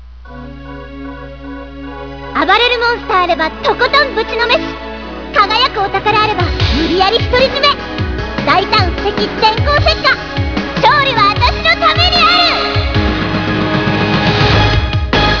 Opening narration in
(notice that the BGM is different, too)